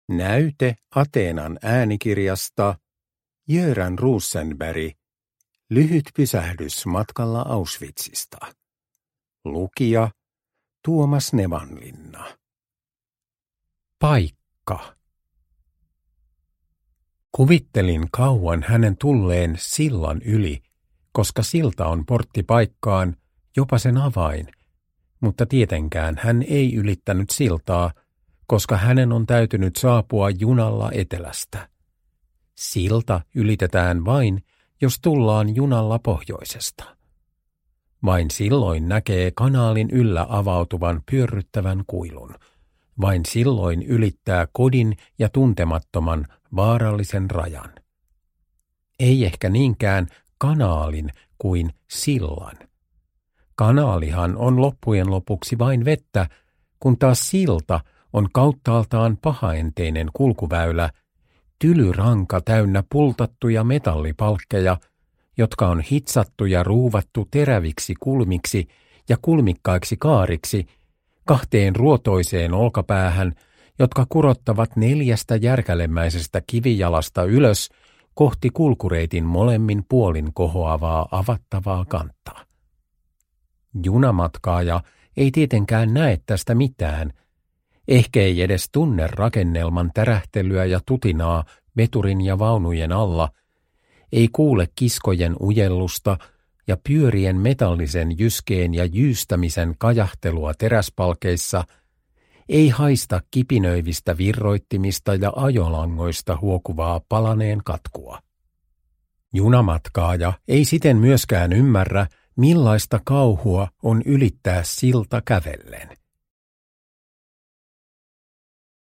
Uppläsare: Tuomas Nevanlinna